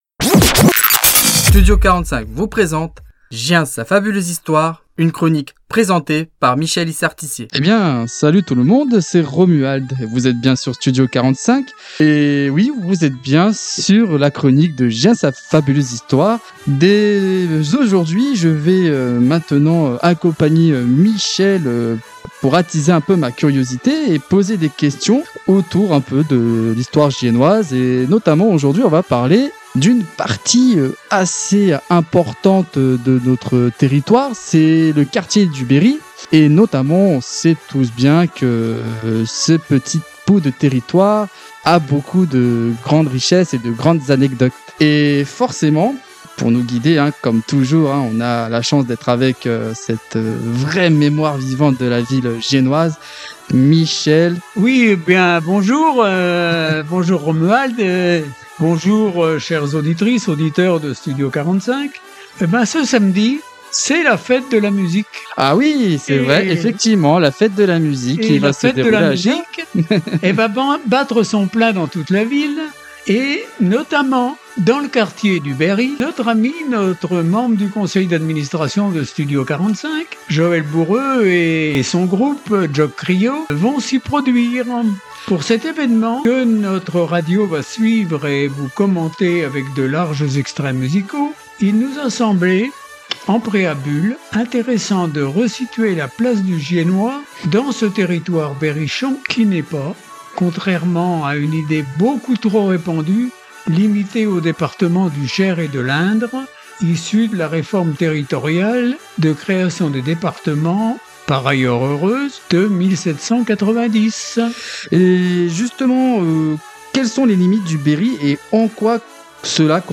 ???? En bonus, découvrez la prestation exceptionnelle de Berry Much, un hymne sur la province berrichonne aux rythmes entraînants !